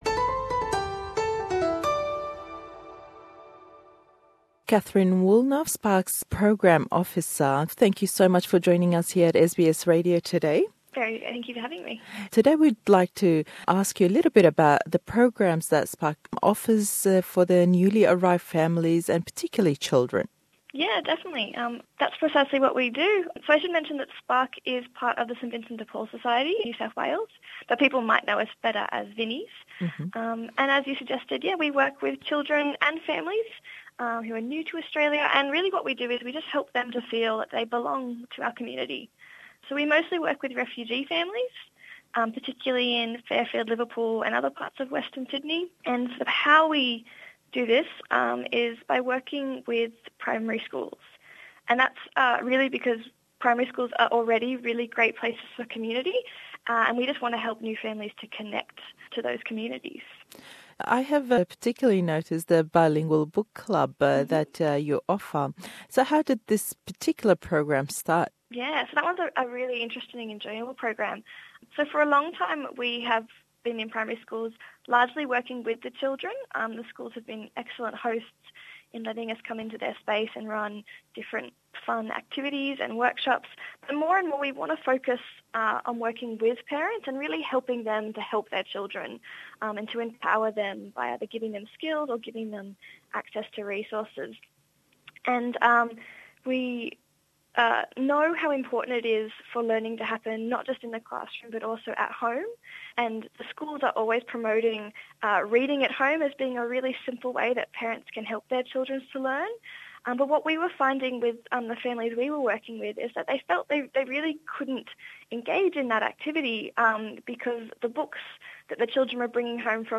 Le em hevpeyvîne le gell karmendî bernamey rêkxirawey SPARK bo pirtûkî dû-ziman